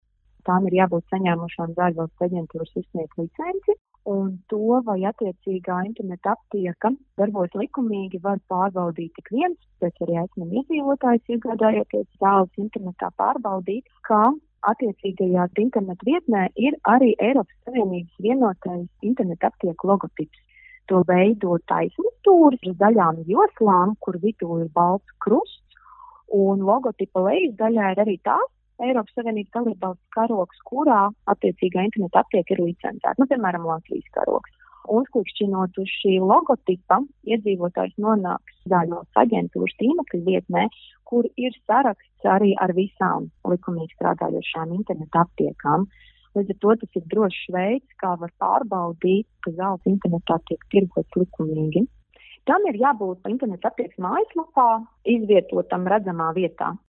RADIO SKONTO Ziņās par to, kā pazīt drošu internetaptieku